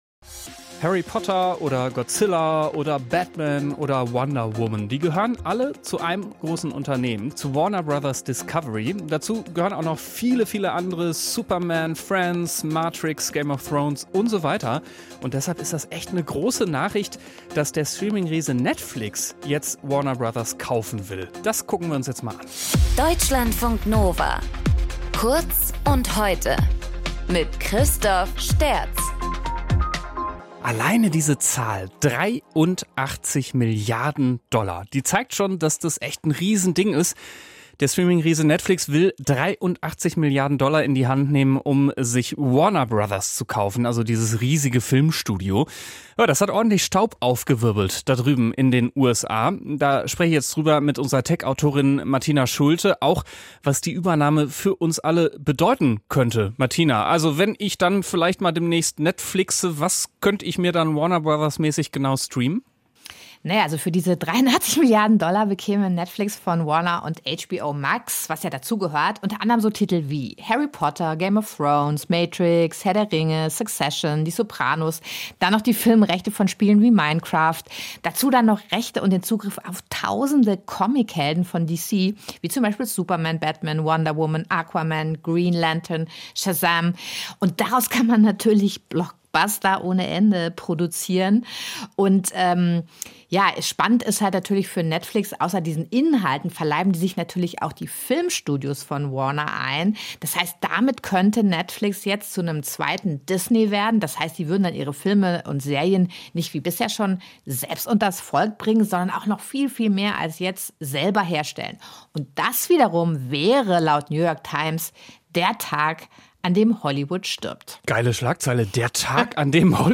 Moderator:
Gesprächspartnerin: